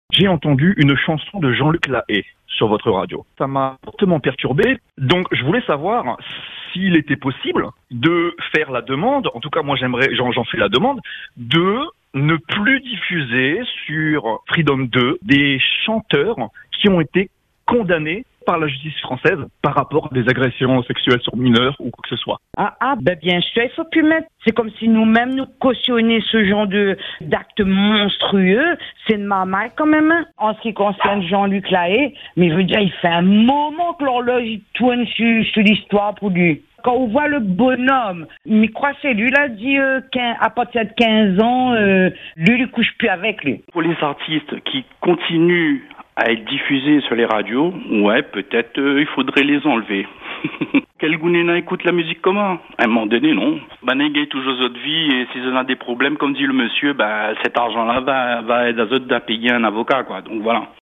Le sujet divise, et vous avez été nombreux à réagir à l’antenne.
Voici un medley de vos avis.